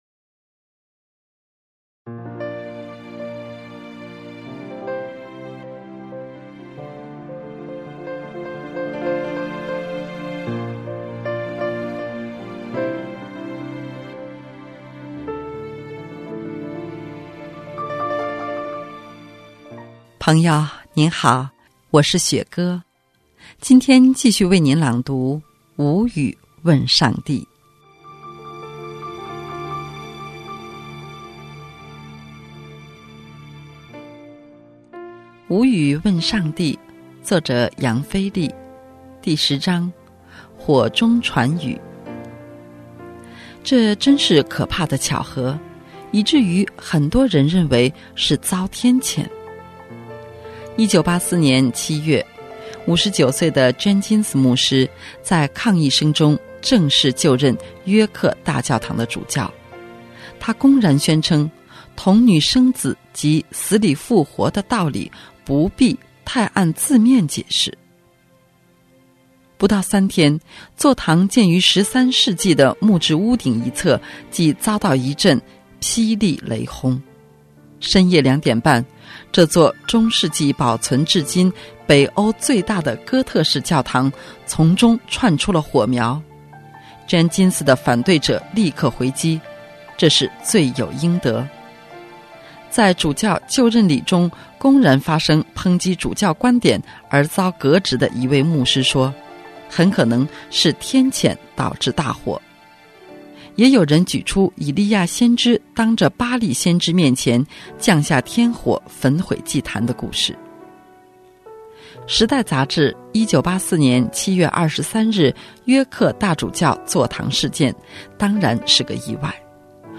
今天继续为您朗读《无语问上帝》。